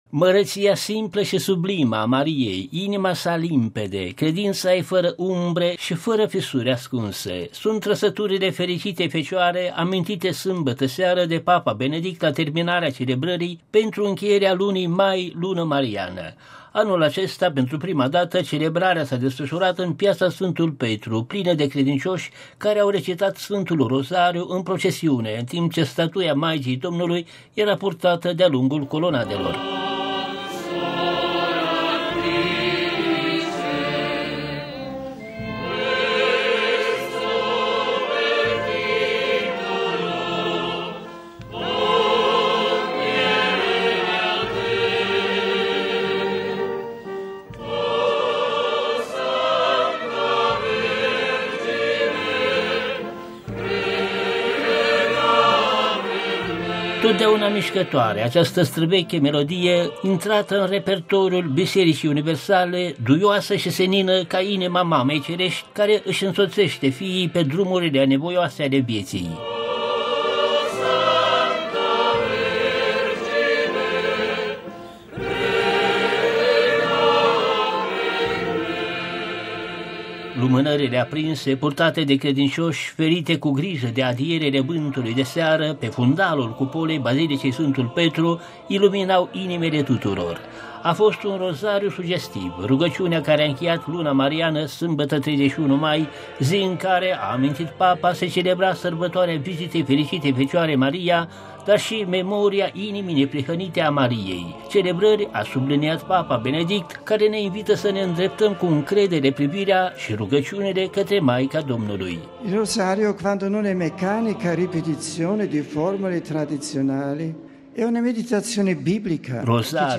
Anul acesta pentru prima dată celebrarea s-a desfăşurat în Piaţa Sfântul Petru, plină de credincioşi care au recitat Sfântul Rozariu în procesiune în timp ce statuia Maicii Domnului era purtată de-a lungul colonadelor.
Secvenţe muzicale "Mira il tuo poplo".
Totdeauna mişcătoare, această străveche melodie intrată în repertoriul Bisericii universale, duioasă şi senină ca inima Mamei cereşti care îşi însoţeşte fiii pe drumurile anevoioase ale vieţii.
• Secvenţe corale finale "Ave Maria".